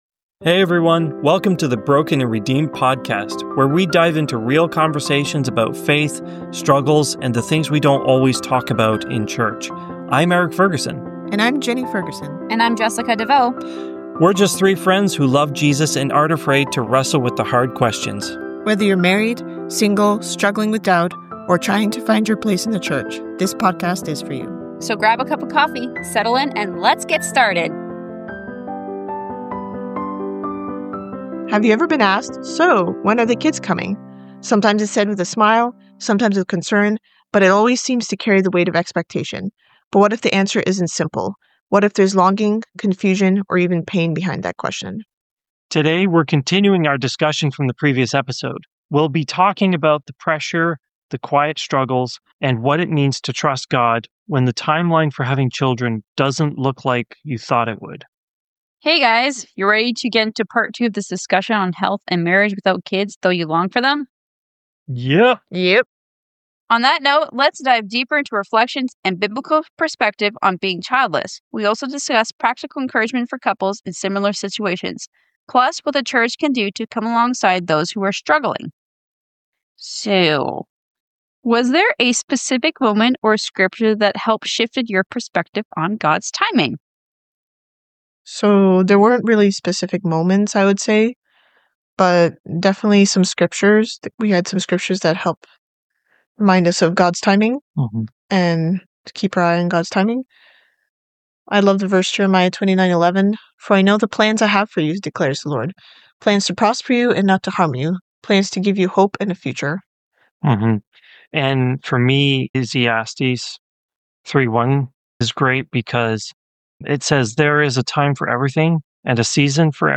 Through honest conversation, this episode explores the challenges and joys of serving in Guatemala, from navigating cultural differences and poverty to witnessing how Christian education can transform the lives of students and their families.